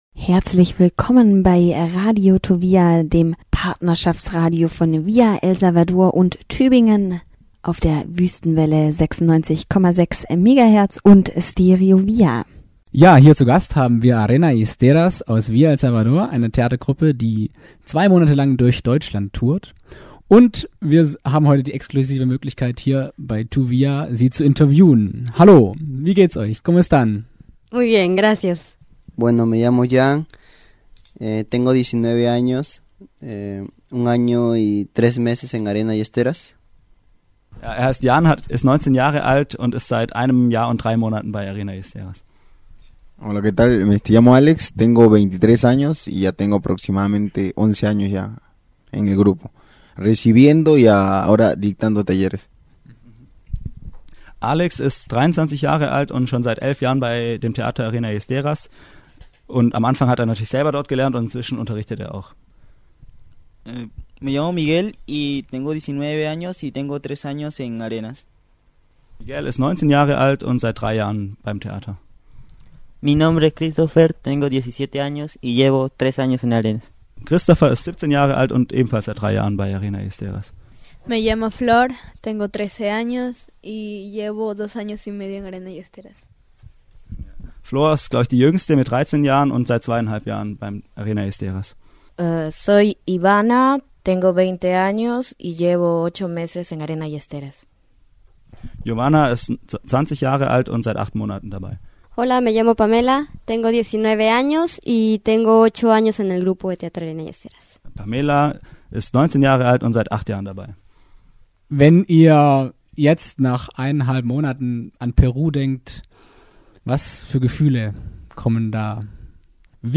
Am 13.10. besuchte uns die Theatergruppe ‘Arena y Esteras‘ aus Tübingens Partnerschaft Villa El Salvador im Studio. Die 13-22 jährigen Peruaner, die seit Ende August 2011 durch Deutschland touren und ihr Stück “Urpillau” aufführen, erzählten über ihre Eindrücke der Deutschen, das Theaterspielen und das jugendliche Leben in Villa El Salvador.